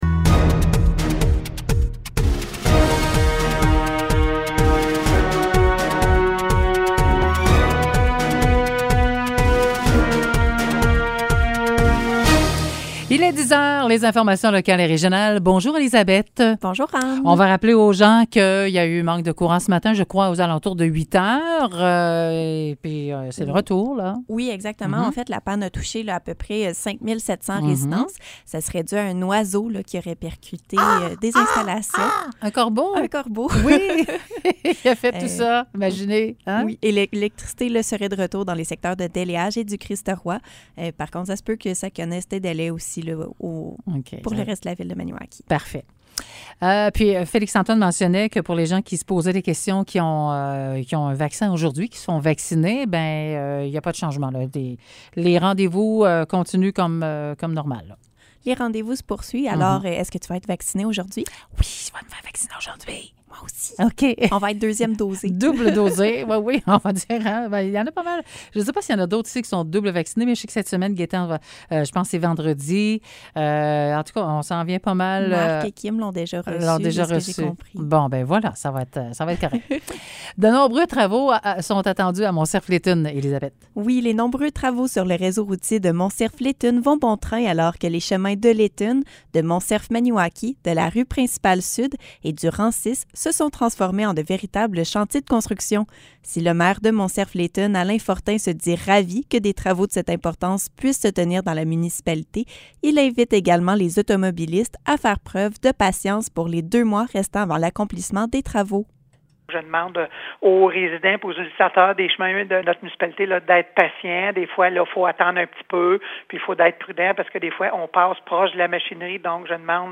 Nouvelles locales - 7 juillet 2021 - 10 h